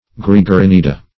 Gregarinida \Greg`a*rin"i*da\